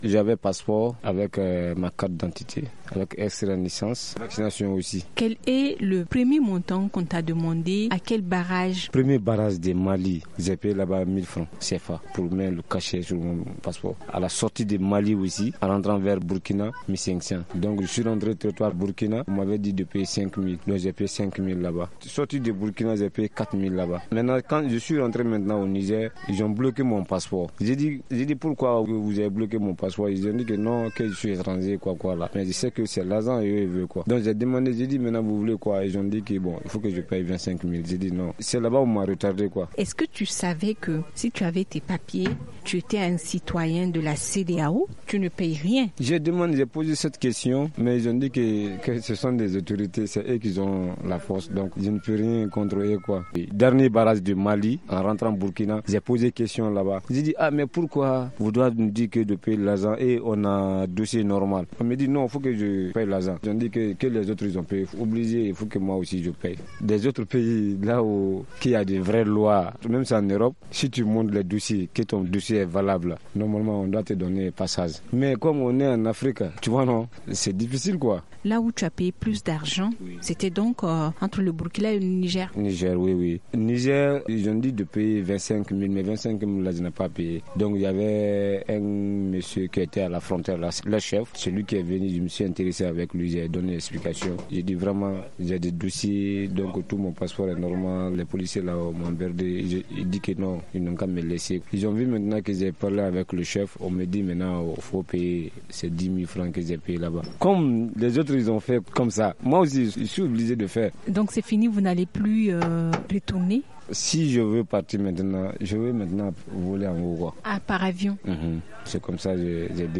Magazine du 20 décembre 2017: Le témoignage d’un jeune Guinéen victime de racket - Studio Kalangou - Au rythme du Niger